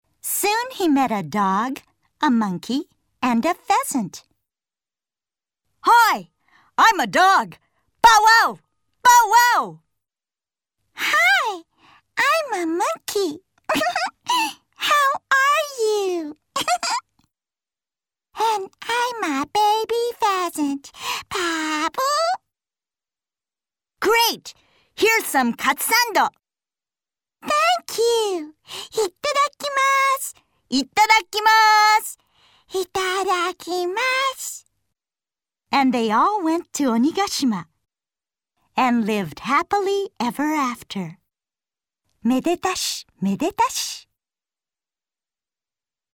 英語ナレーター（アメリカ英語/米語・日本語）ボイスサンプル 日英 自己紹介 英語 ナレーション 英語 キャラクターボイス（桃太郎#1) 英語 キャラクターボイス（桃太郎#2) 日英 キャラクター・歌 英語 歌 HOME 利用規約・免責事項 お問い合わせ・お見積もり